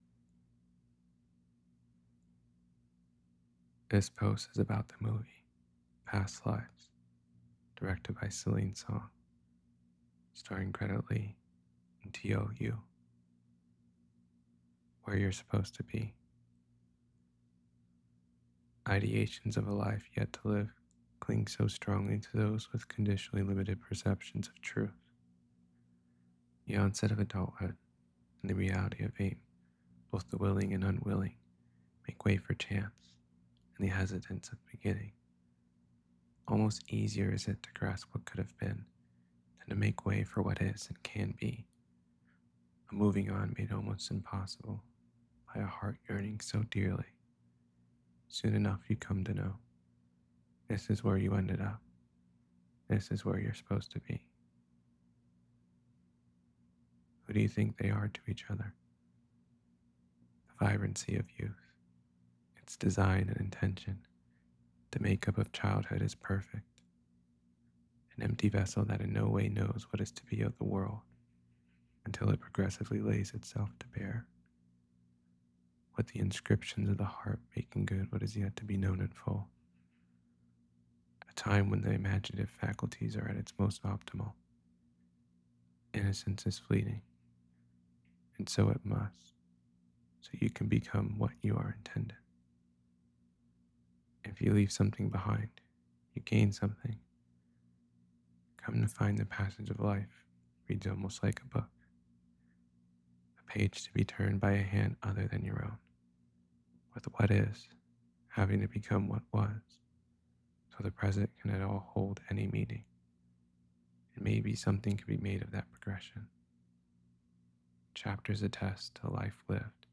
past-lives-to-know-a-story-reading.mp3